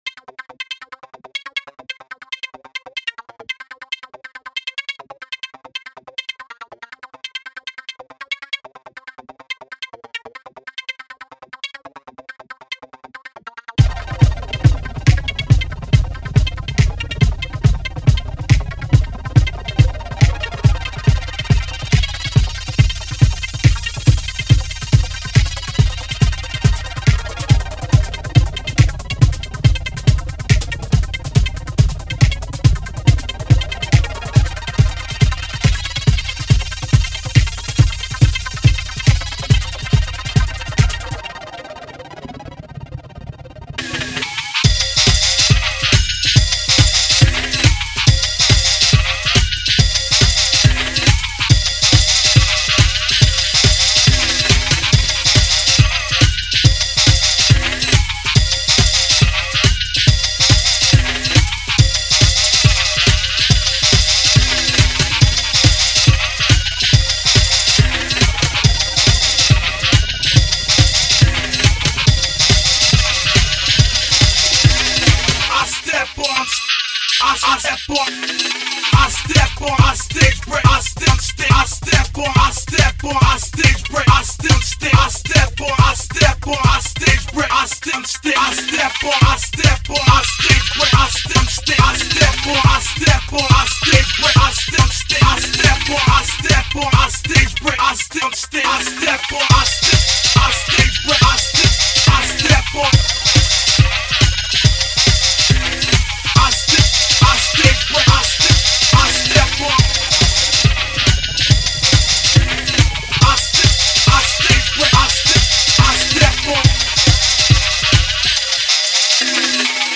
О, это жесткая электронная музыка.